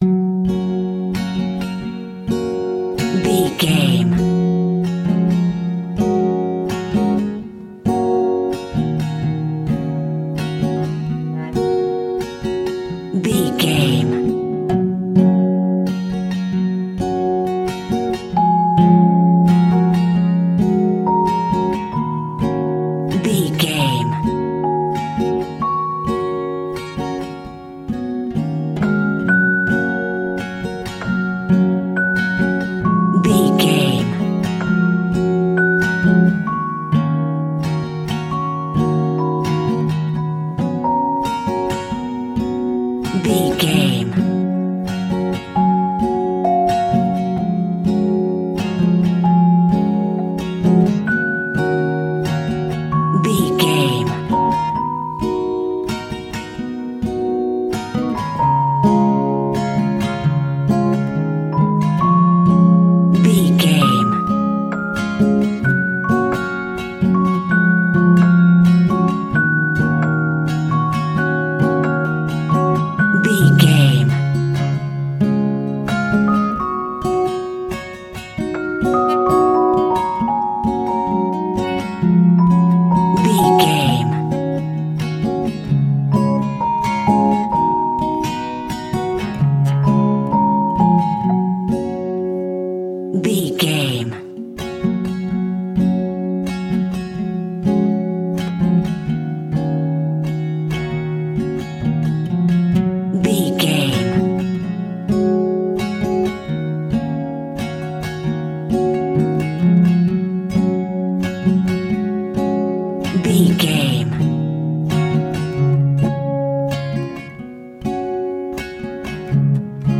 Ionian/Major
childrens music
acoustic guitar
xylophone